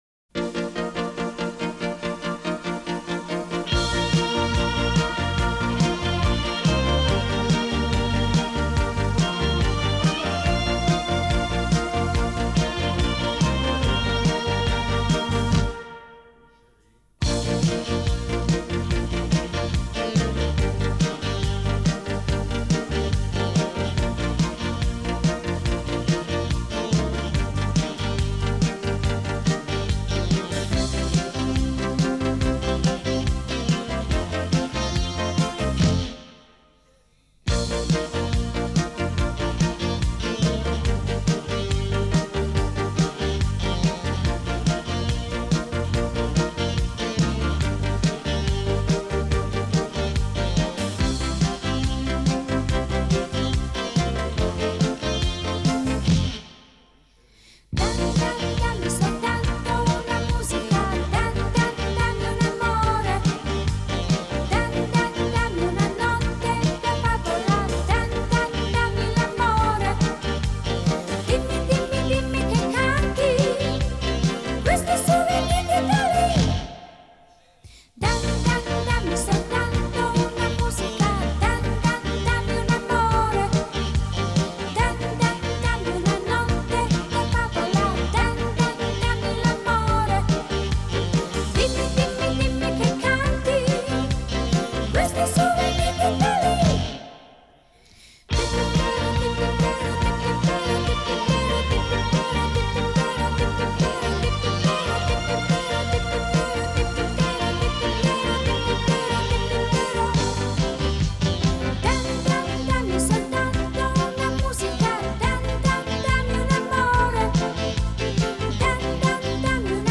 минусовка версия 122503